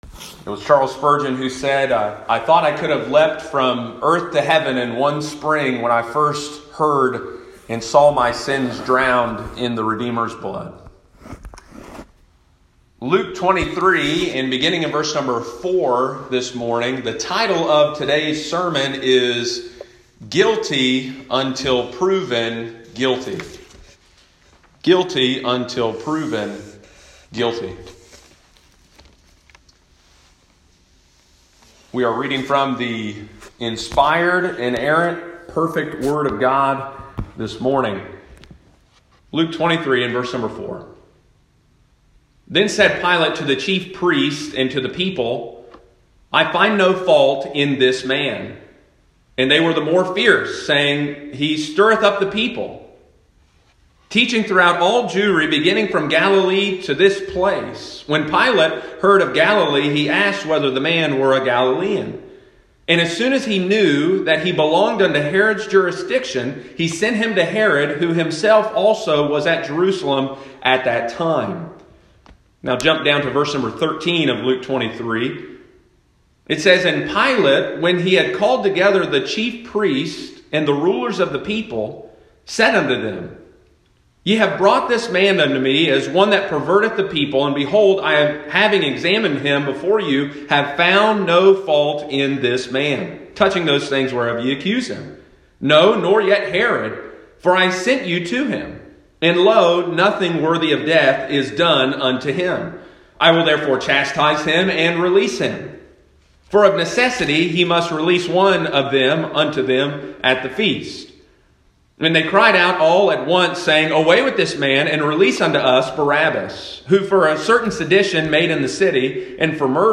easter-2020.m4a